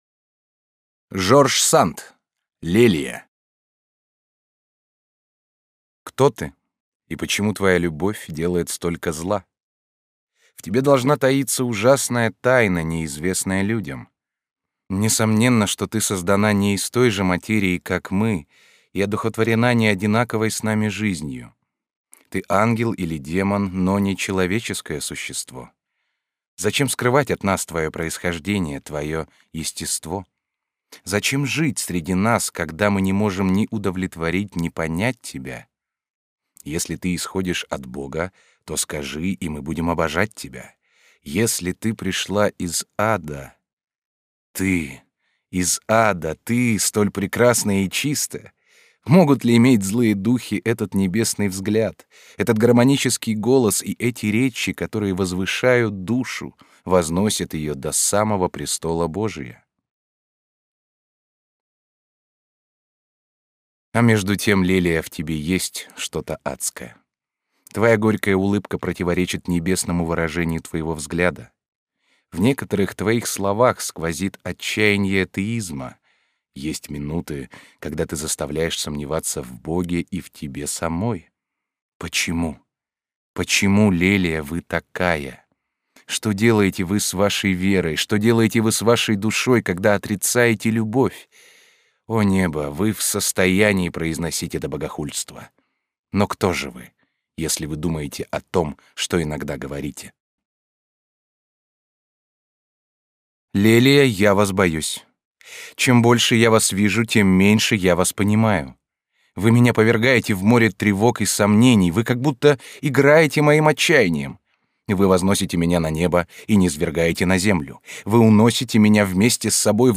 Аудиокнига Лелия | Библиотека аудиокниг